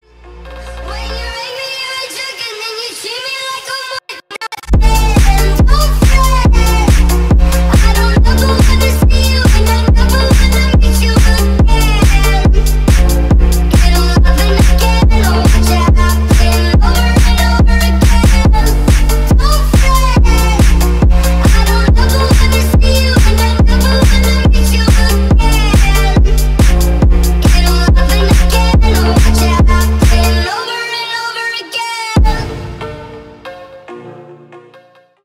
Рэп рингтоны
басы
phonk